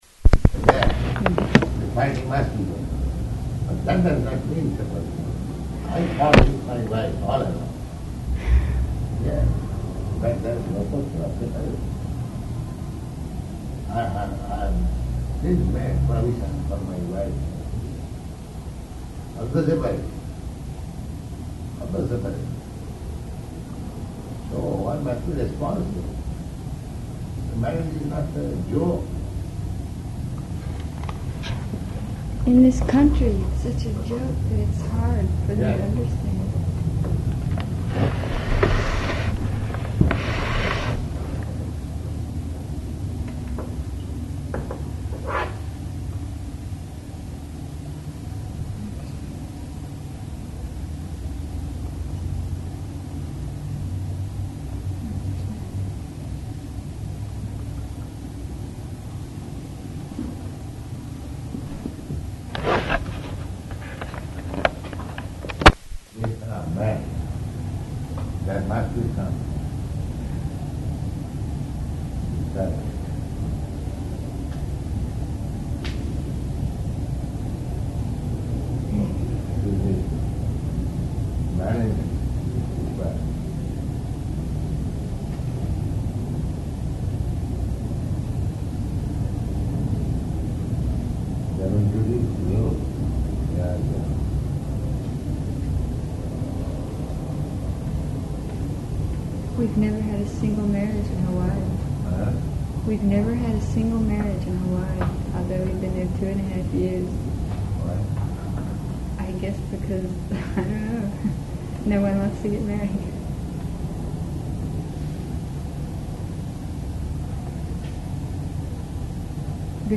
Type: Conversation
Location: Los Angeles